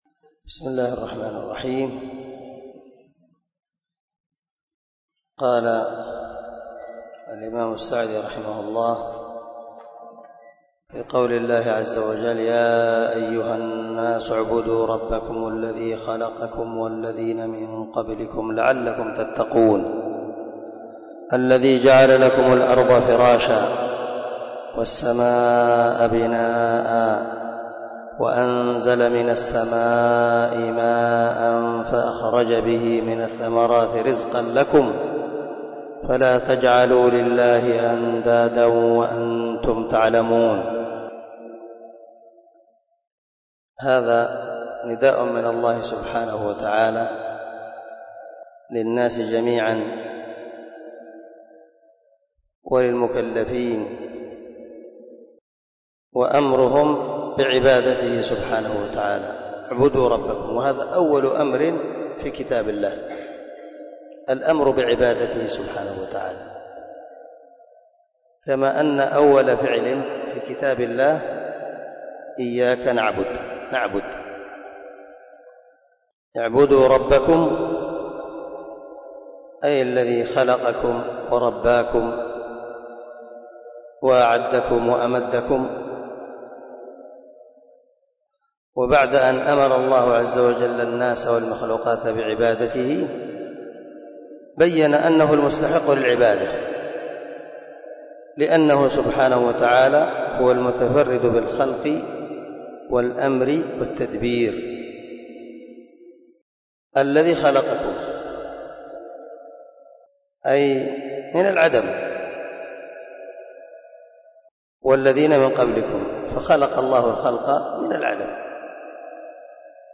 دار الحديث- المَحاوِلة- الصبي